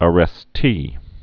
(ə-rĕs-tē)